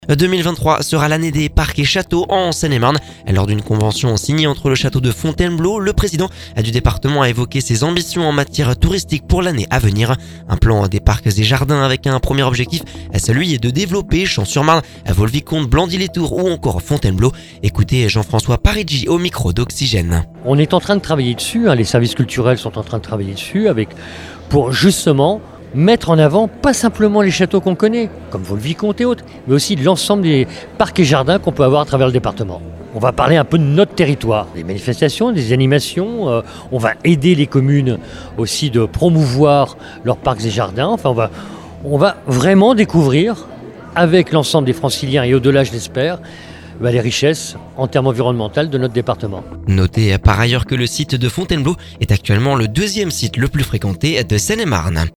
Ecoutez Jean François Parigi au micro d’Oxygène…